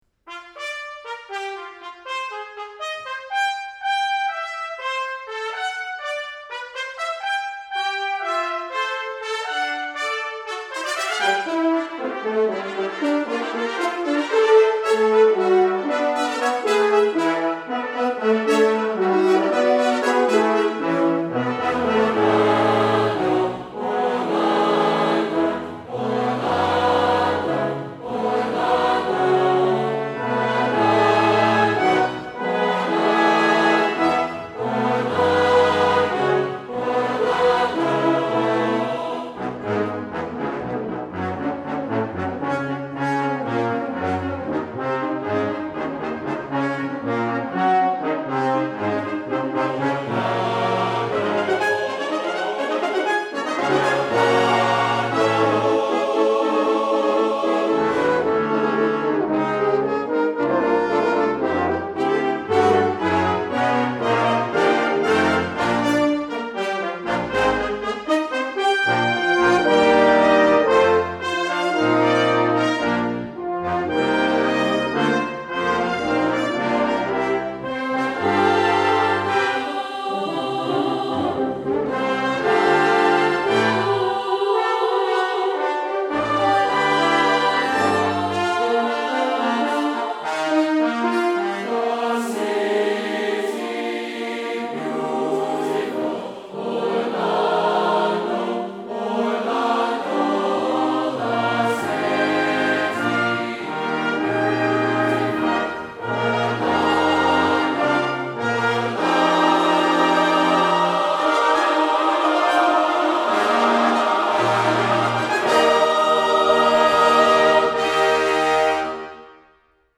As the work develops, the themes intertwine.
for Brass and Choir